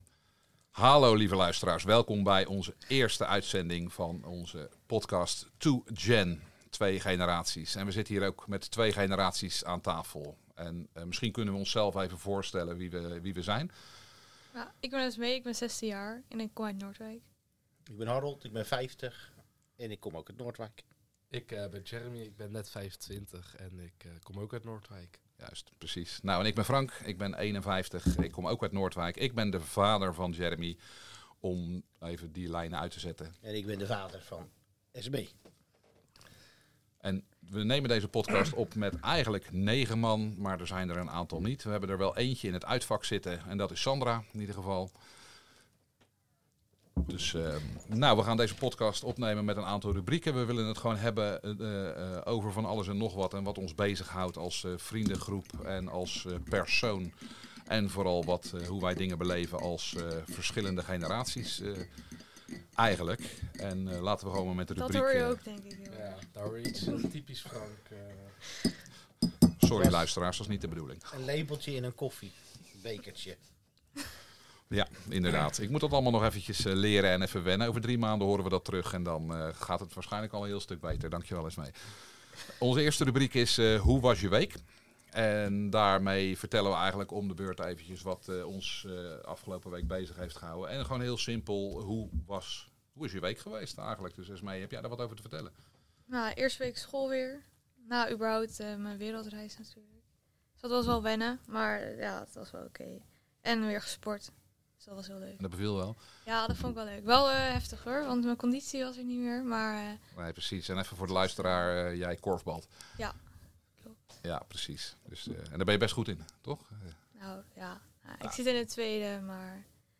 2Gen is een podcast met twee generaties. In de eerste aflevering twee vaders, één dochter en één zoon.